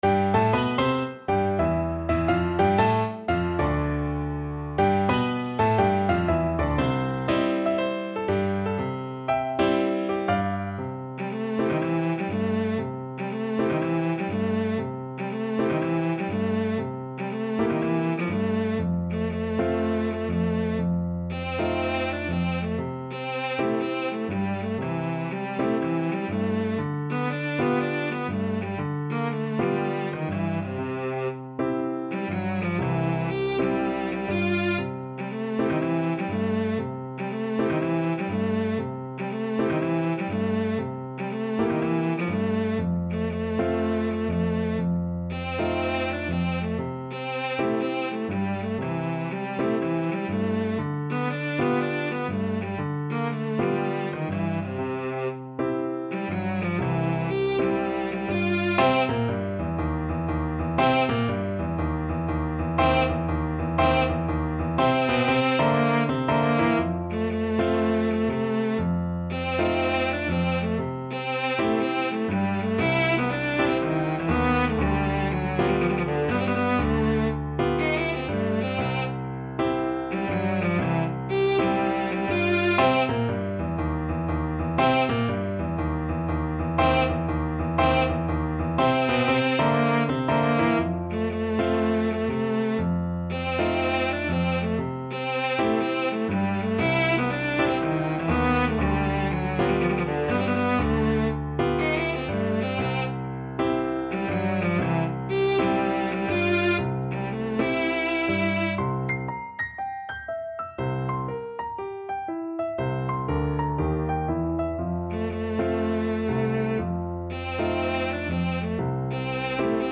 Cello
4/4 (View more 4/4 Music)
C major (Sounding Pitch) (View more C major Music for Cello )
Moderato = 120
Jazz (View more Jazz Cello Music)